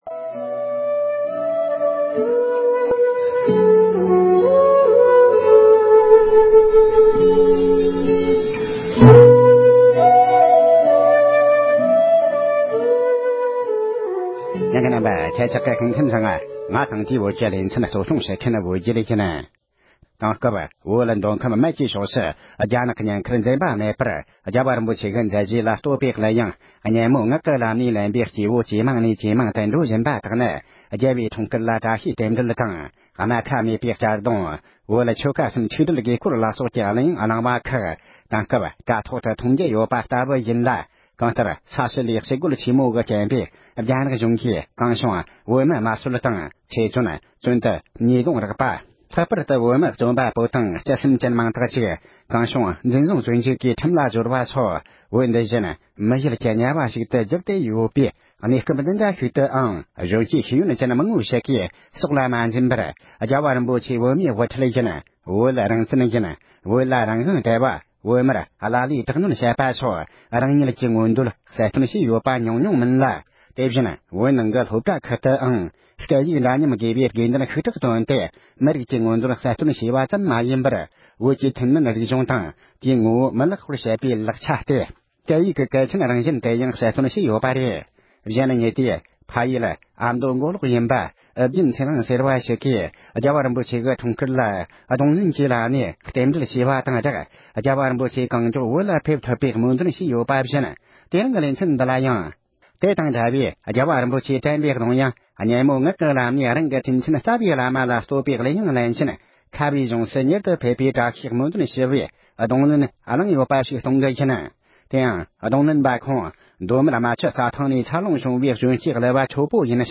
རྡུང་ལེན།